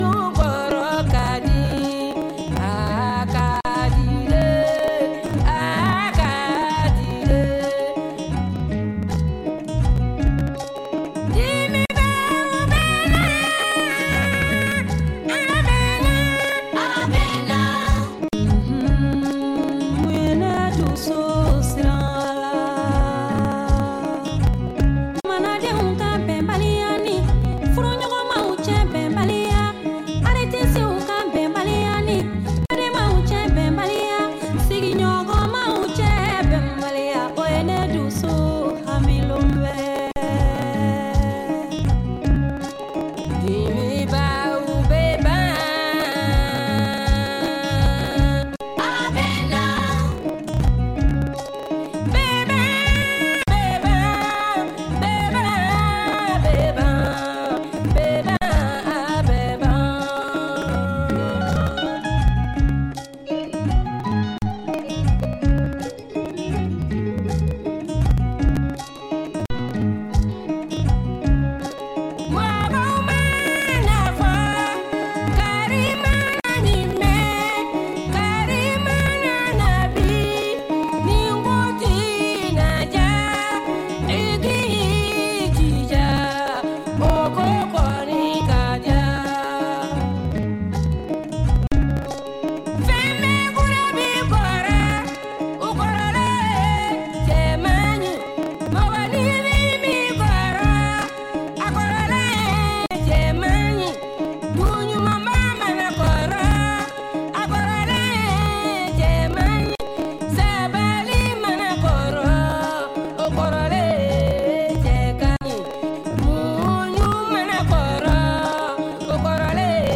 Recorded in Bamako with a full traditional-pop ensemble.
Powerful musical arrangements